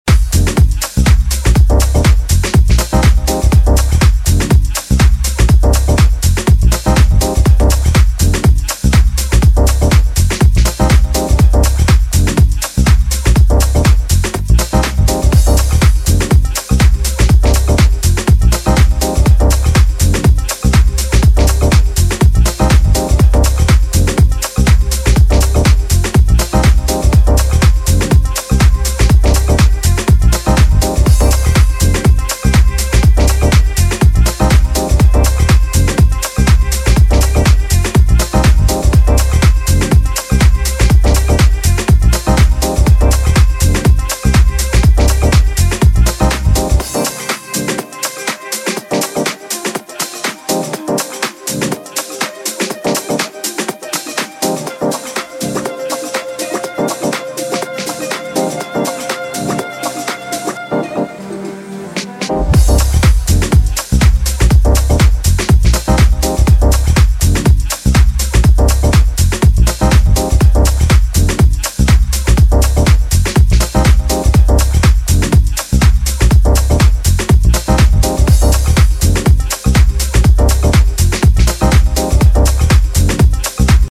sleek and smooth mid-tempo groove
more dancefloor friendly, deep, infectious, jazzy groove